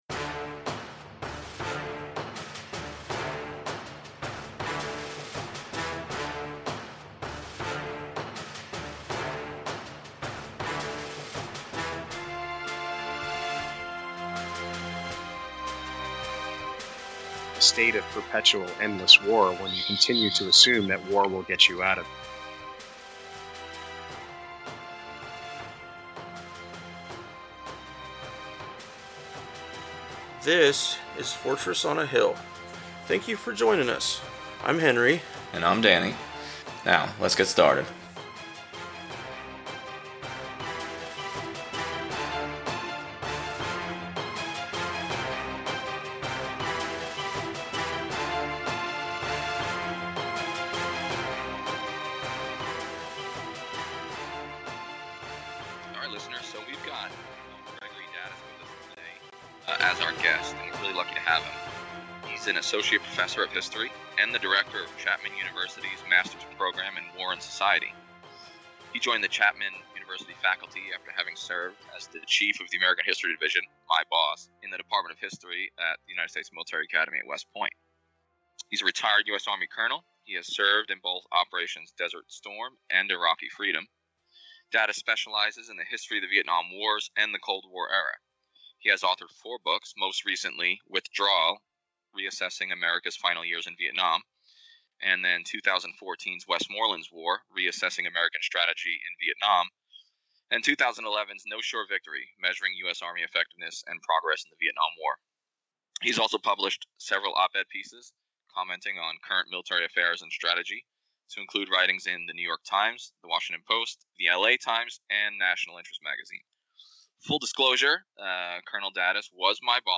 It’s interview time today!!!!